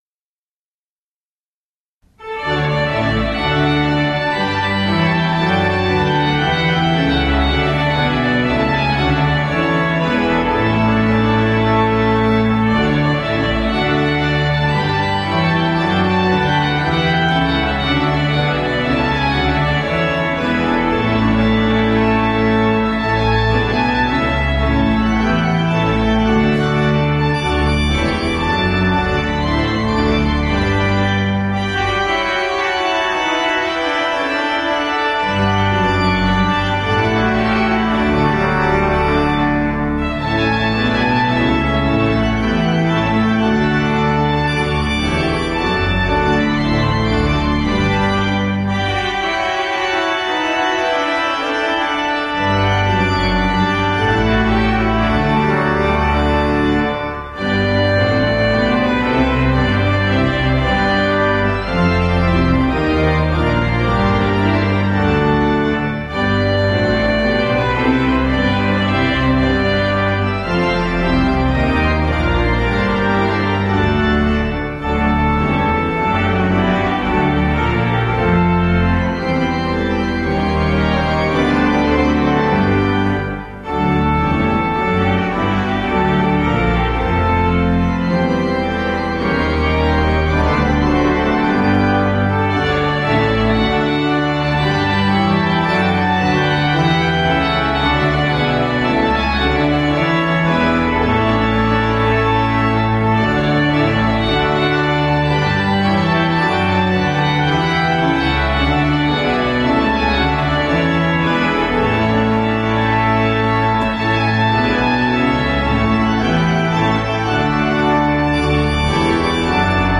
All other tracks are at St Anns Manchester.